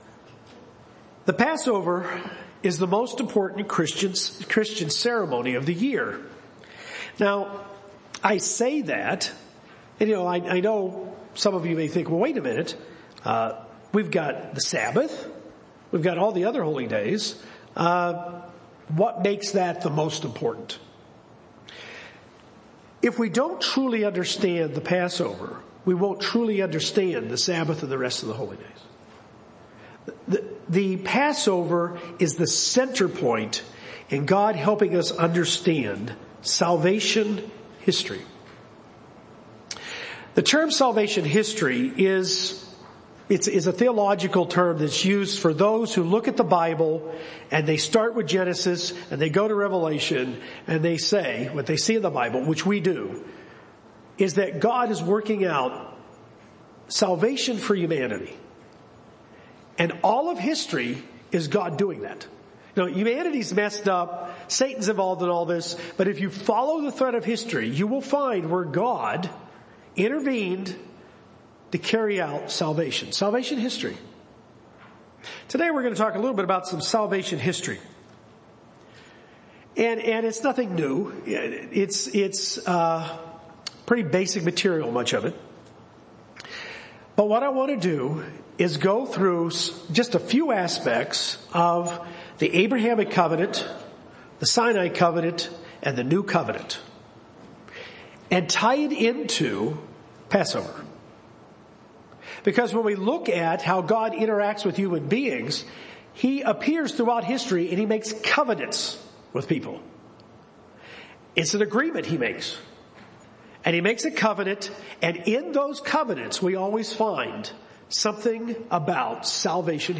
God intervenes in human history to carry out salvation, making covenants with humans to carry the plot further. This sermon explains how the Abrahamic Covenant, Sinai Covenant and New Covenant all have signs of salvation at work, specifically in light of Passover.